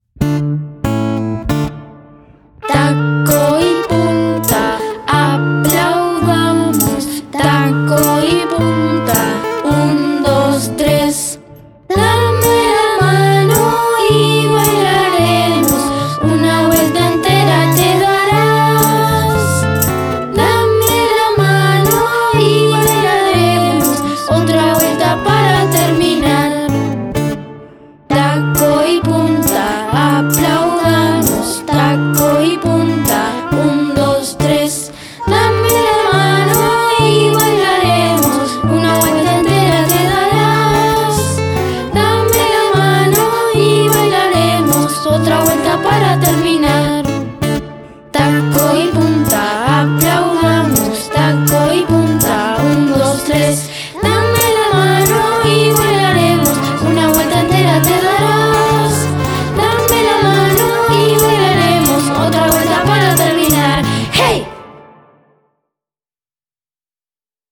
Una ronda para bailar tal como lo indica la letra.